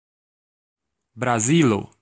Ääntäminen
Etsitylle sanalle löytyi useampi kirjoitusasu: Brazil brazil Vaihtoehtoiset kirjoitusmuodot Brasil Ääntäminen US UK : IPA : /bɹəˈzɪl/ US : IPA : /bɹəˈzɪl/ Lyhenteet ja supistumat Braz (laki) Braz.